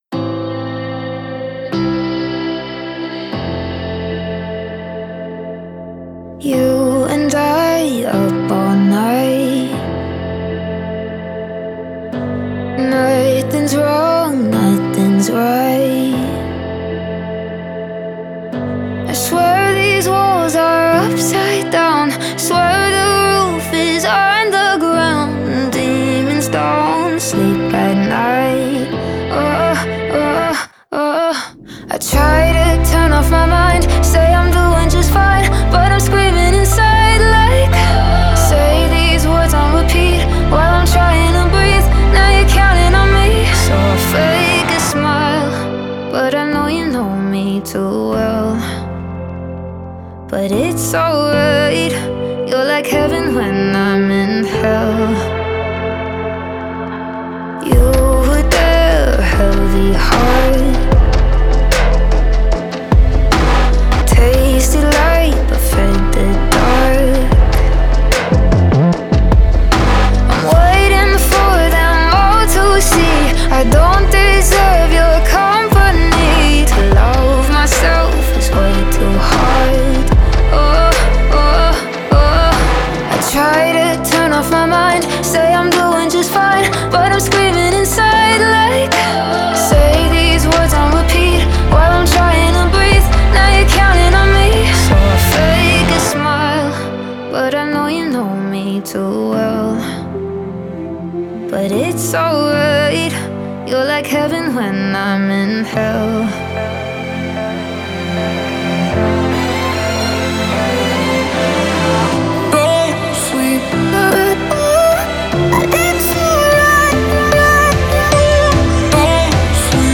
энергичная электронная поп-песня